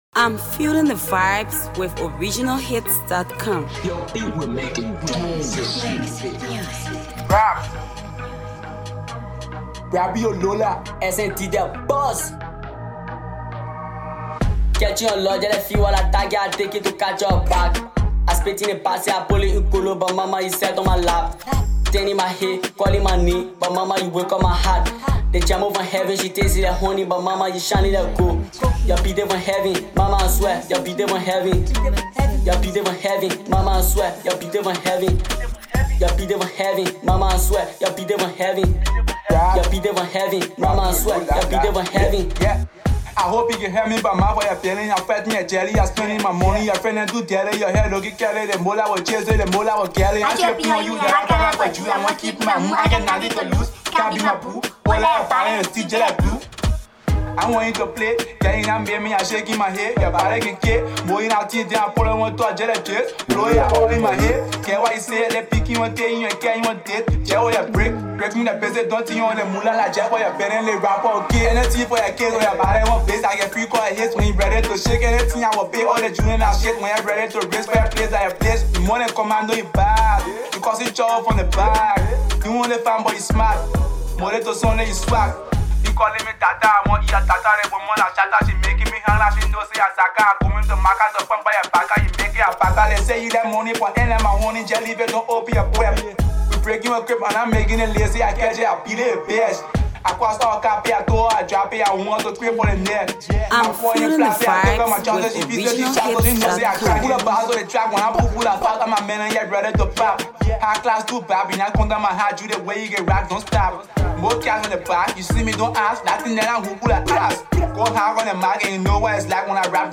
New trap song is available for y’all speaker
rap song